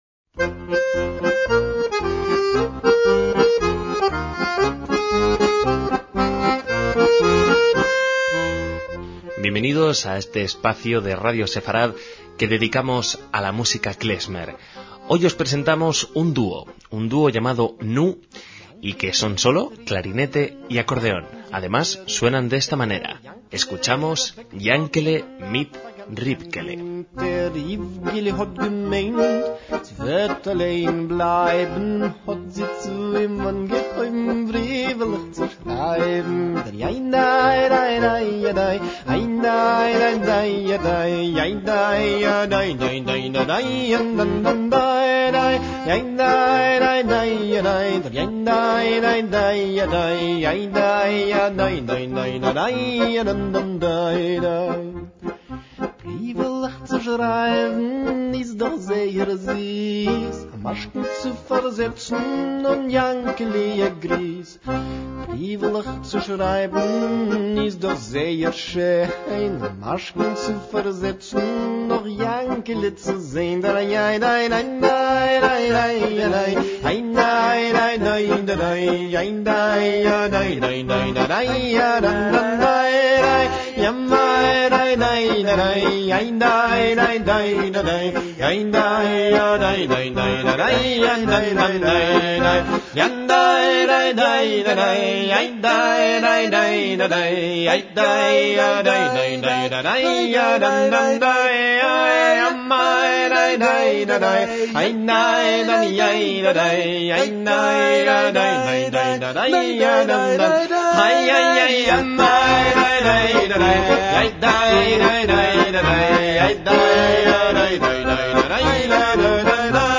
MÚSICA KLEZMER
clarinete y canto